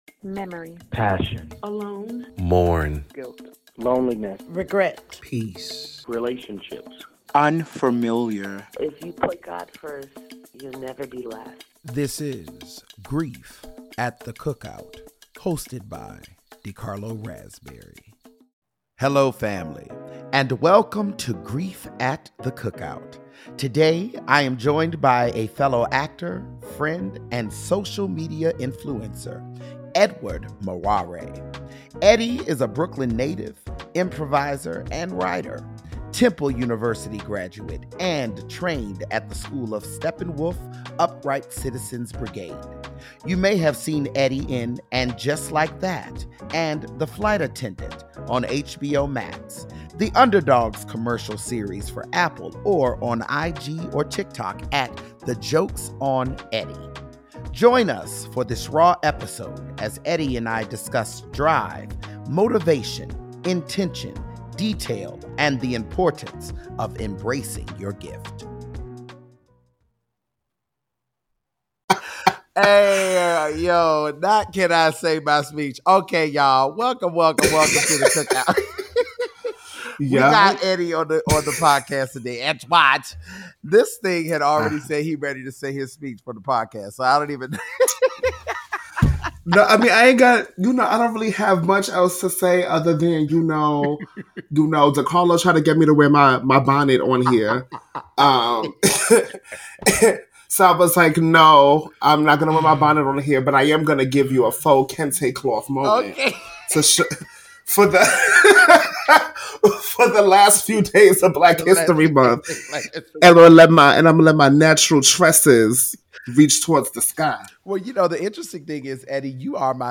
Real, Raw, Honest Conversation...